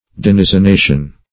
Denizenation \Den`i*zen*a"tion\, n. Denization; denizening.